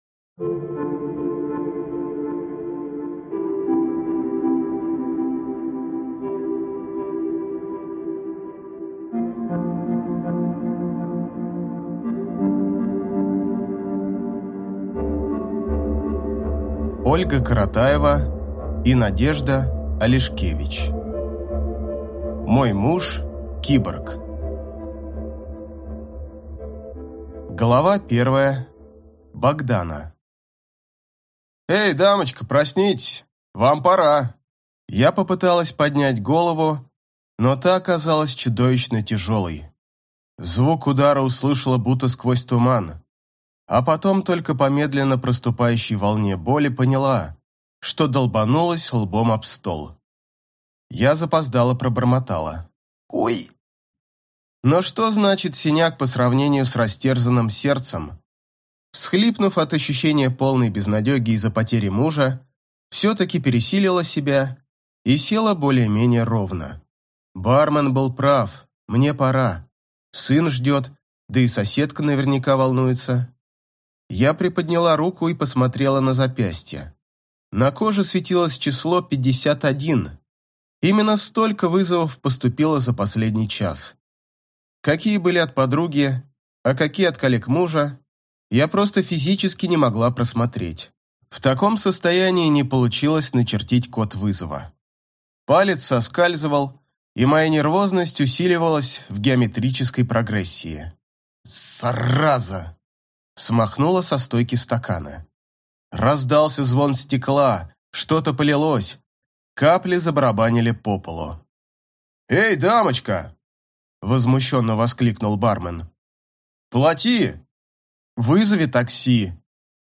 Аудиокнига Мой муж – киборг | Библиотека аудиокниг